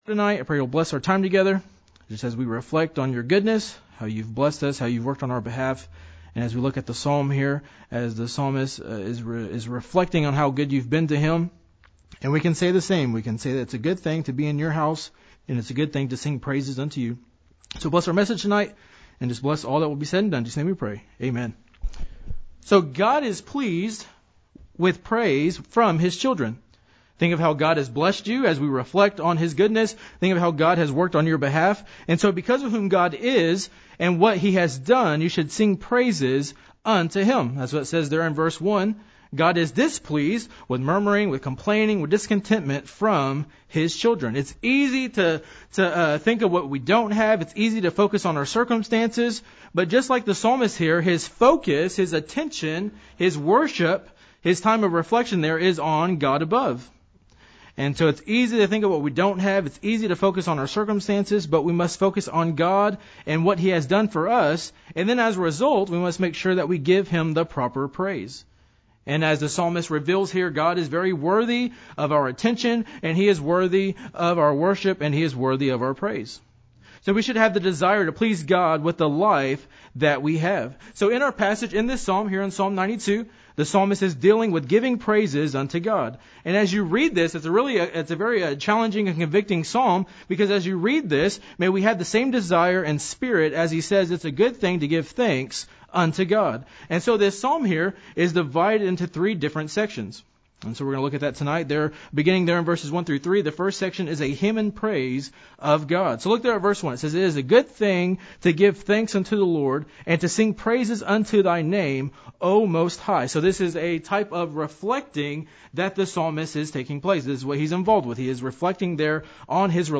preaches through Psalm 92. It is a good thing to bring praise to God for he surely has been good to us.